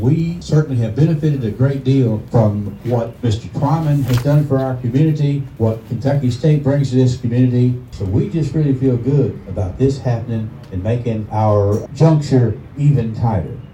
Several county and city officials spoke, including Glasgow Mayor Henry Royse, who reminded those in attendance that Luska J. Twyman was an alumnus of the university as was Louie Nunn.